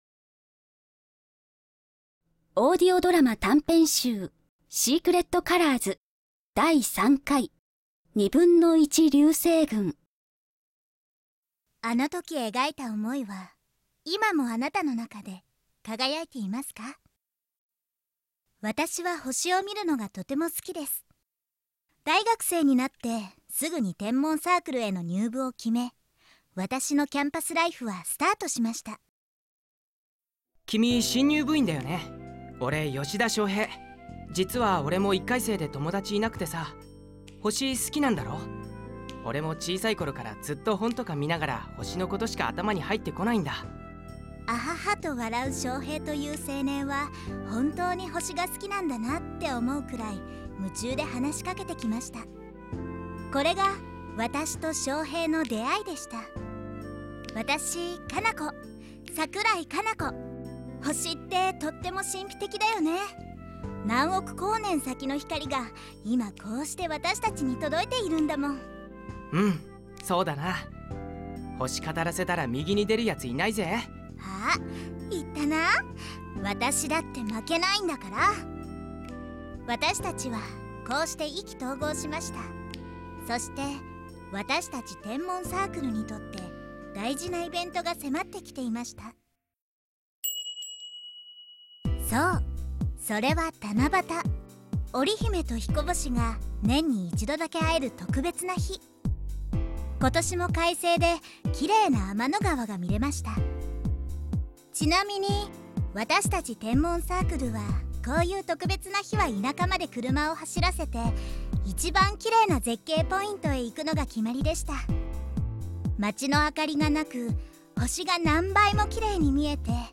１／２流星群 – オーディオドラマ「１／２流星群」 – Podcast – Podtail